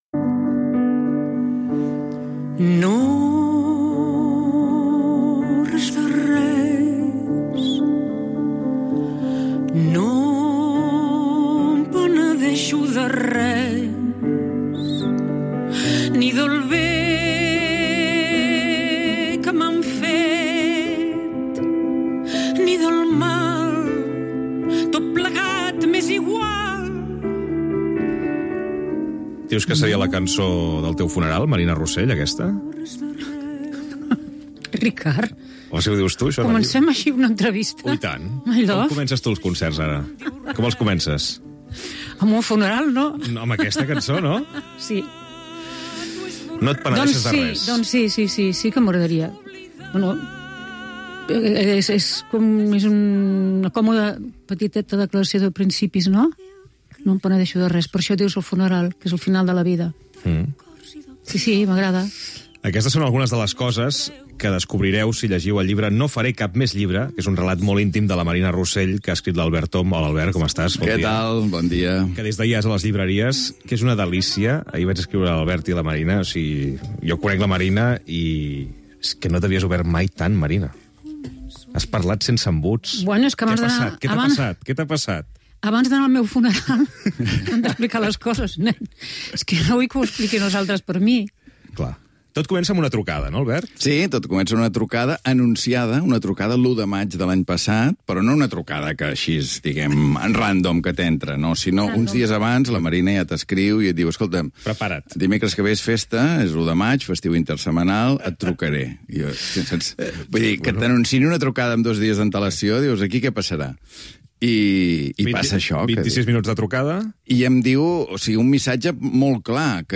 Fragment d'una entrevista a la cantant Marina Rossell amb motiu de la publicació de "No faré cap més llibre" escrit per Albert Om.
Info-entreteniment